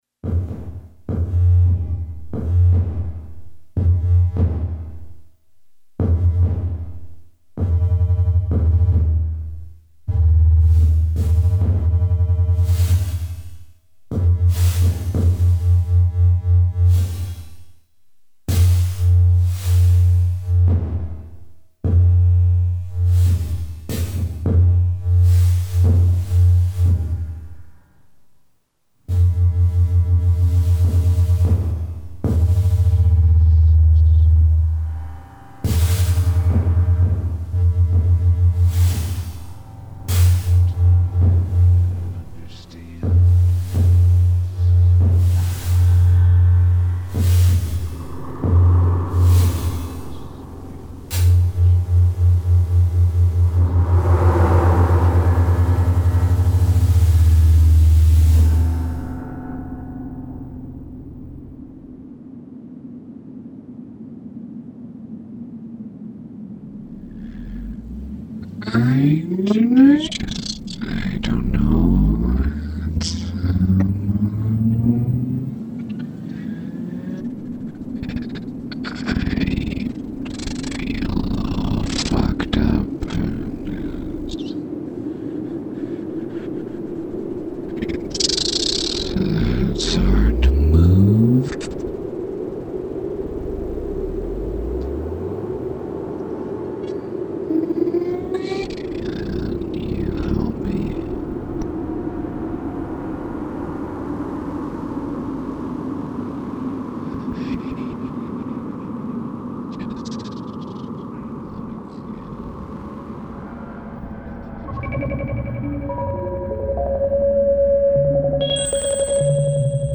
Stereo mixes of 4 channel sound installation in Shadowgut.